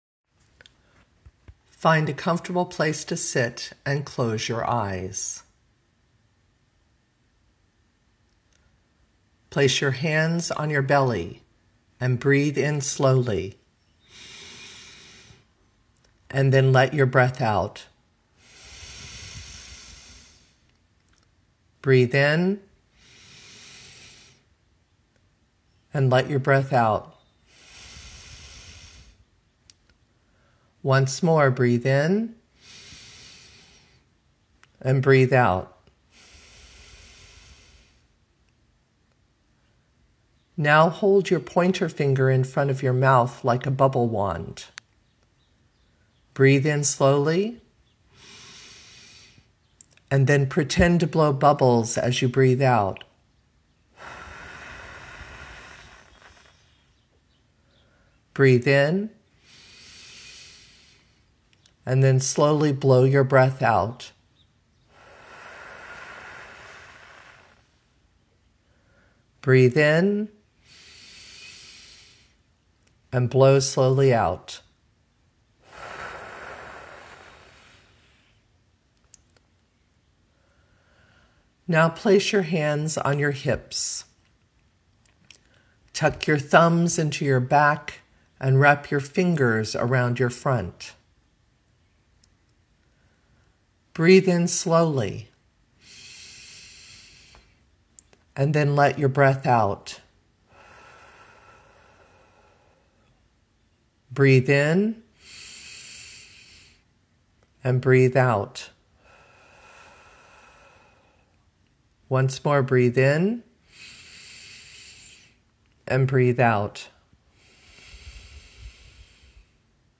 Breath Meditation